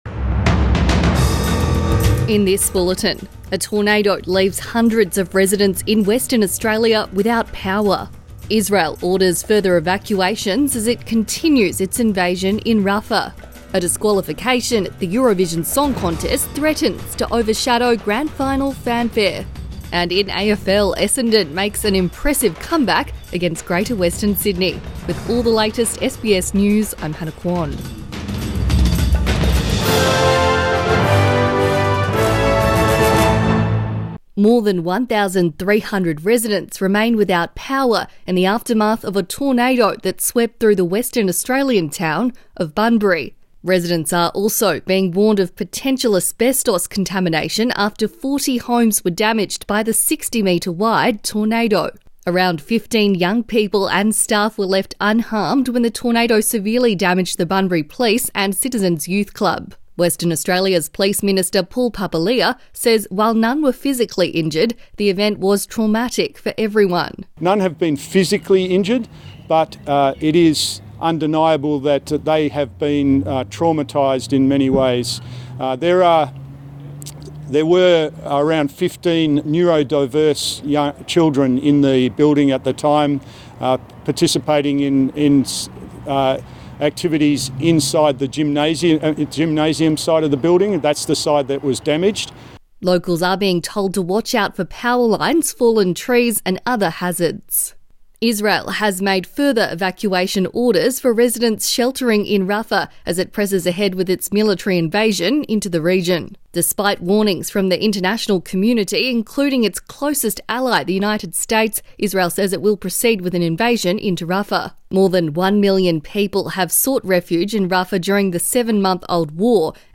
Morning News Bulletin 12 May 2024